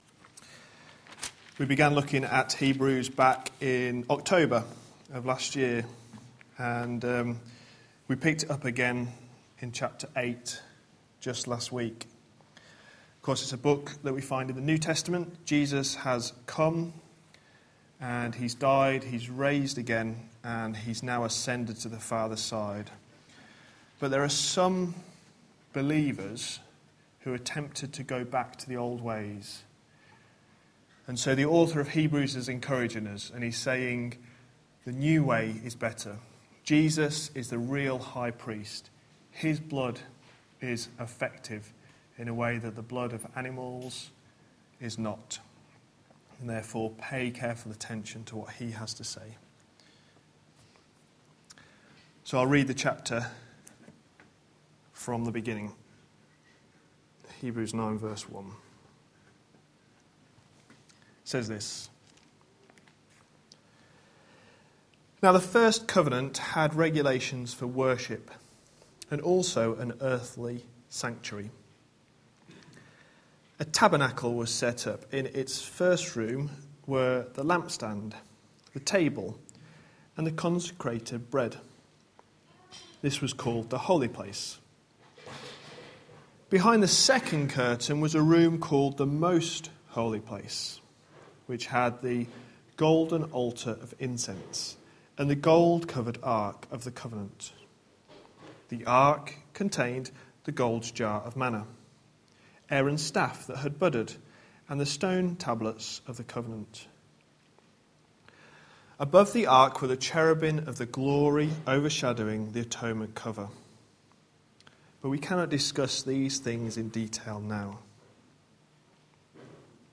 A sermon preached on 8th February, 2015, as part of our Hebrews series.